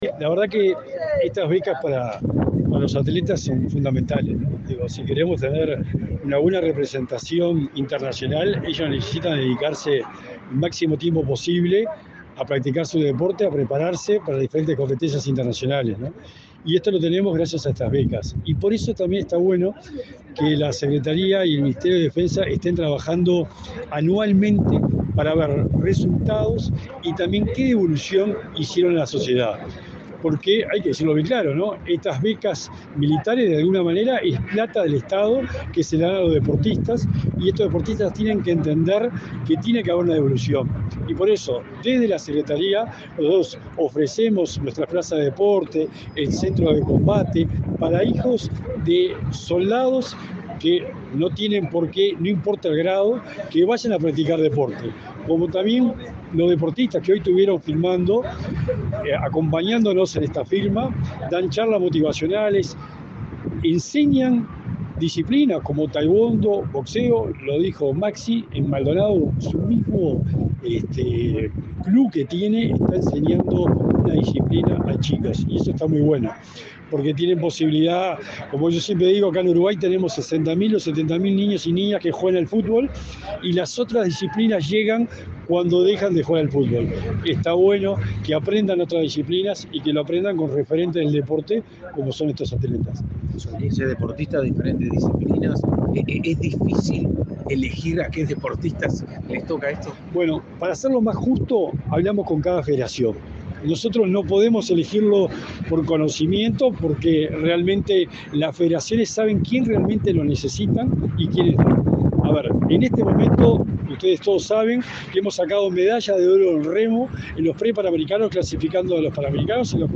Declaraciones del secretario nacional del Deporte, Sebastián Bauzá
Declaraciones del secretario nacional del Deporte, Sebastián Bauzá 18/04/2023 Compartir Facebook X Copiar enlace WhatsApp LinkedIn El secretario del Deporte, Sebastián Bauzá, y el ministro de Defensa, Javier García, firmaron un convenio, con el objetivo de promover el desarrollo deportivo y la especialización en prácticas de alto rendimiento para deportistas federados que ocupen vacantes militares en dicha cartera. Luego Bauzá dialogó con la prensa.